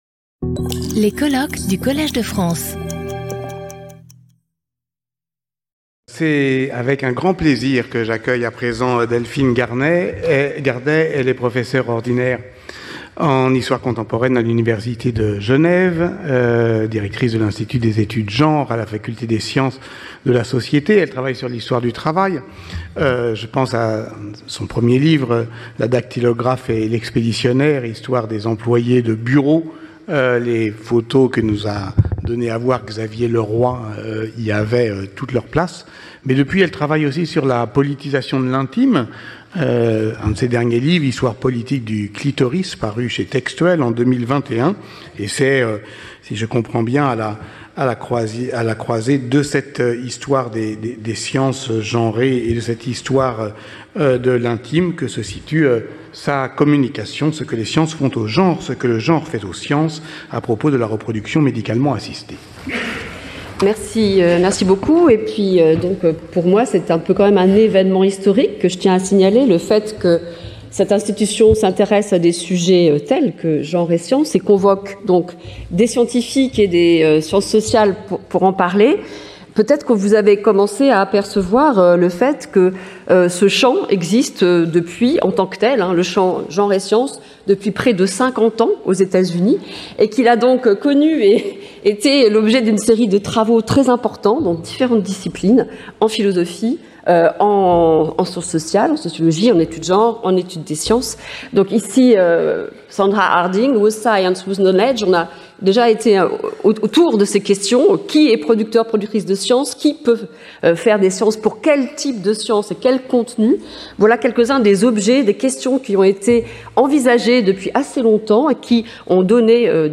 Sauter le player vidéo Youtube Écouter l'audio Télécharger l'audio Lecture audio Séance animée par Patrick Boucheron. Chaque communication de 30 minutes est suivie de 10 minutes de discussion.